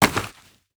Dirt footsteps 11.wav